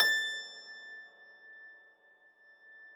53k-pno21-A4.wav